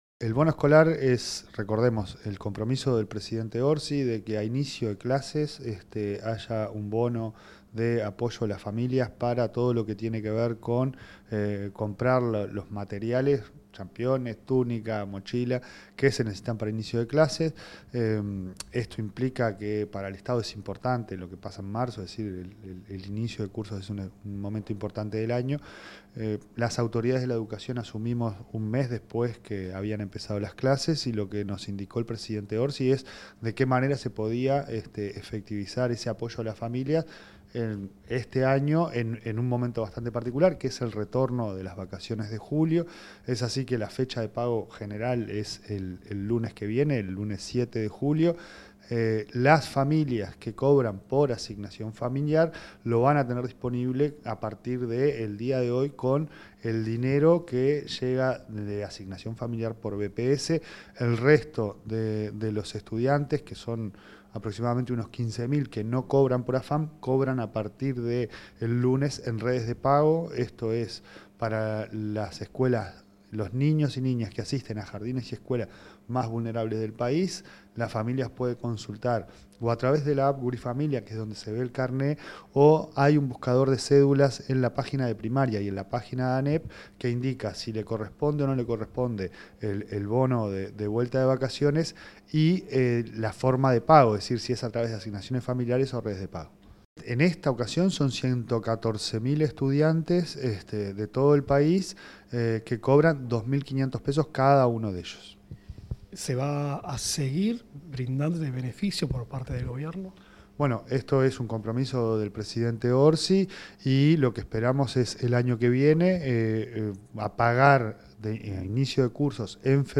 Declaraciones del presidente de la ANEP, Pablo Caggiani